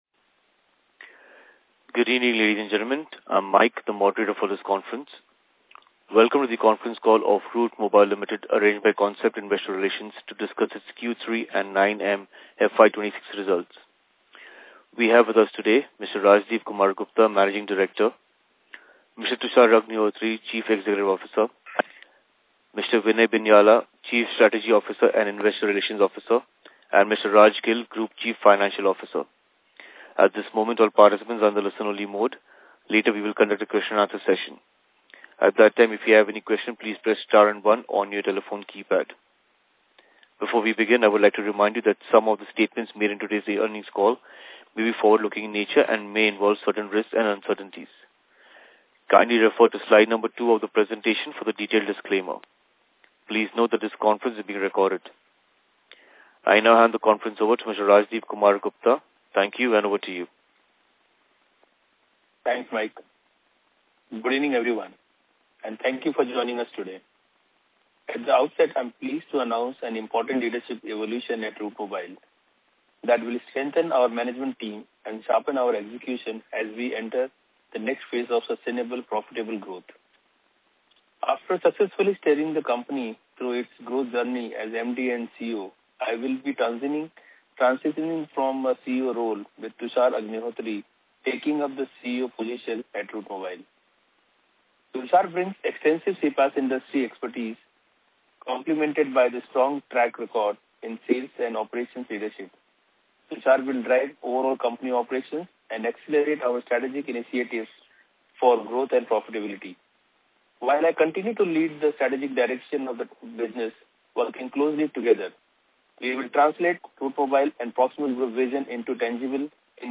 Concalls
Q3Concall-Route-Mobile-Ltd-2026.mp3